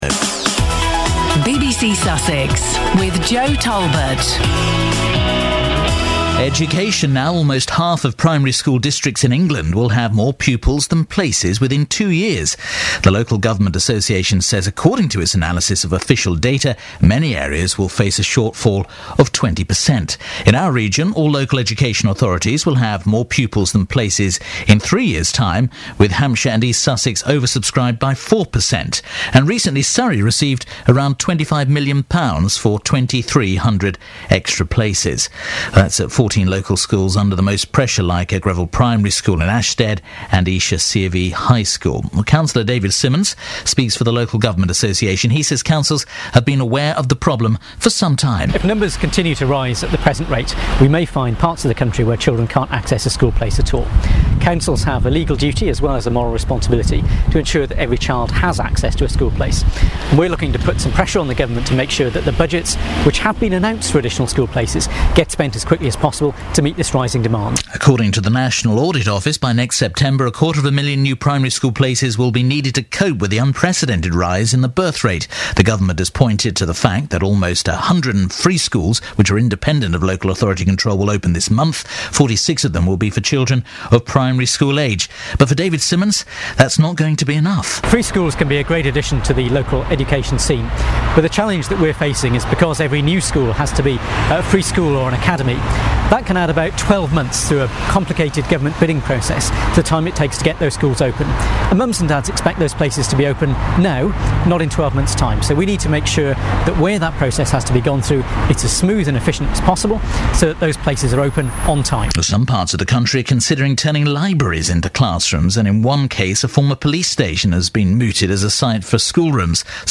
BBC interview on Surrey’s biggest ever school investment programme
Cabinet Member for Schools and Learning Linda Kemeny has been interviewed by the BBC about the 2,800 classroom places created for the new academic year as part of the biggest school investment programme Surrey has ever seen. The county council is providing 16,000 places to meet an increase in demand following a surge in the birth rate.
Audio courtesy of BBC Surrey.